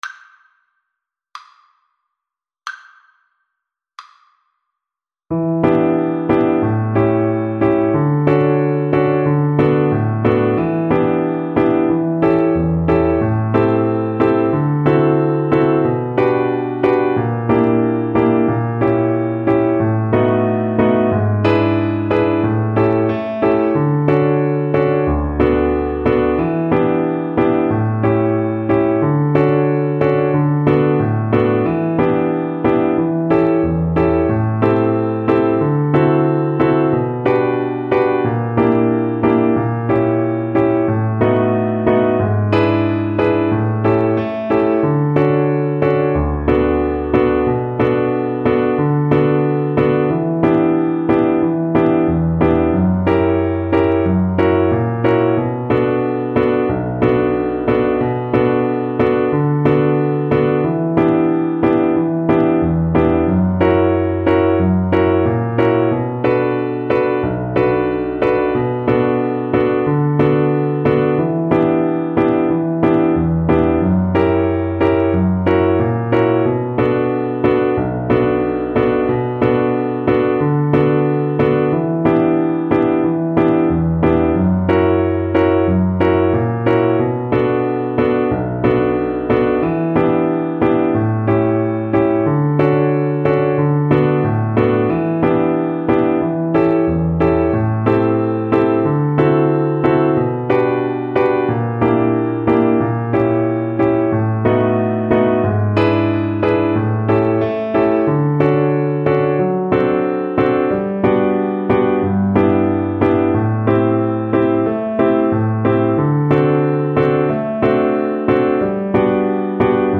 2/4 (View more 2/4 Music)
Brazilian Choro for Violin